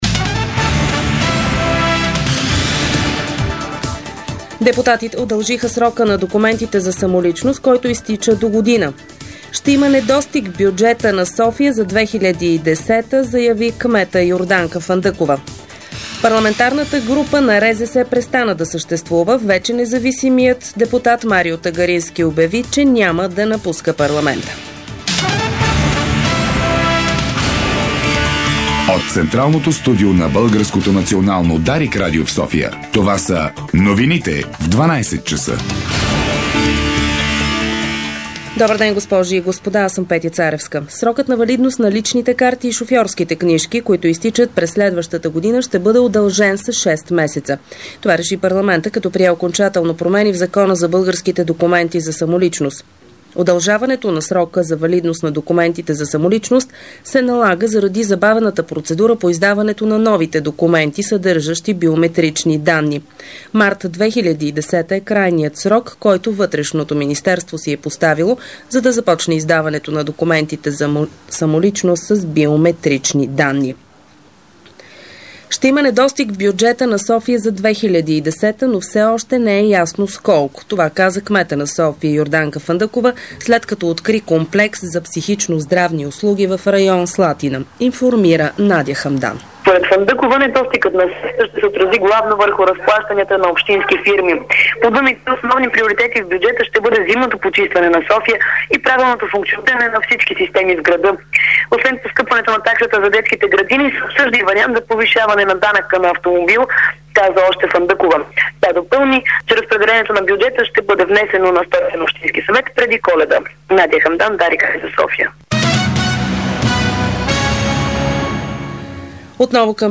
Обедна информационна емисия - 09.12.2009